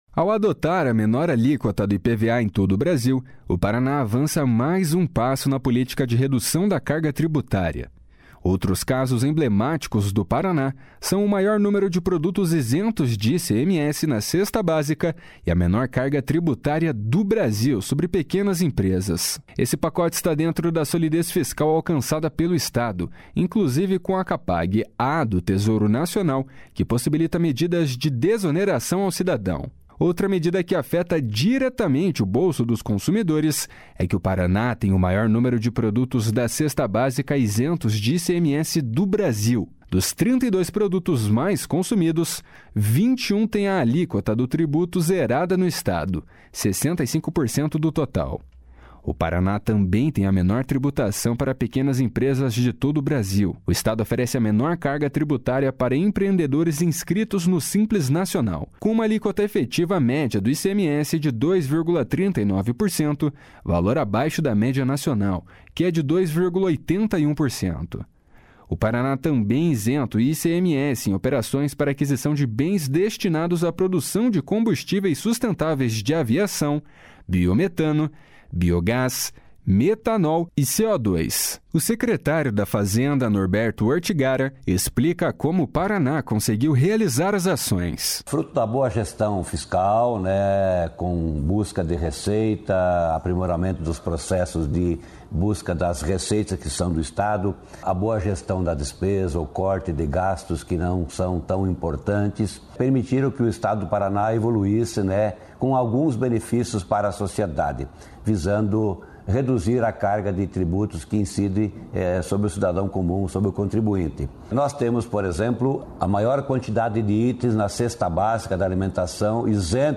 O secretário da Fazenda, Norberto Ortigara, explica como o Paraná conseguiu realizar as ações. // SONORA NORBERTO ORTIGARA //